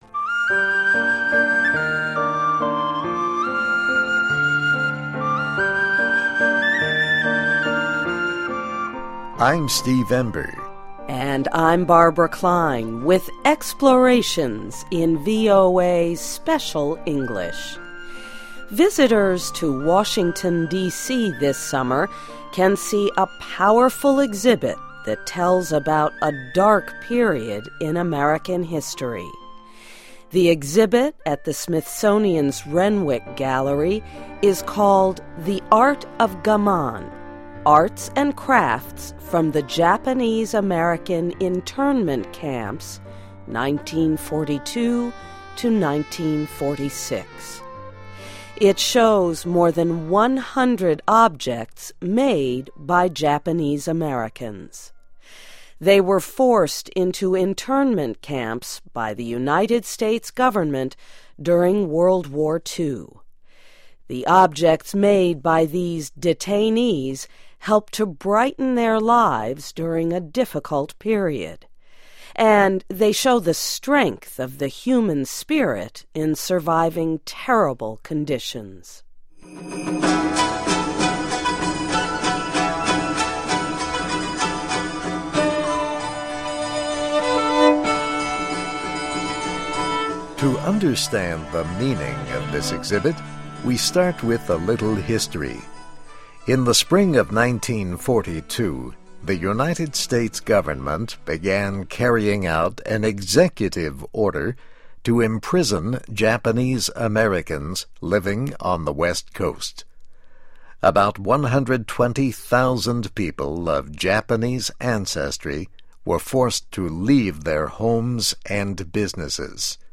(MUSIC)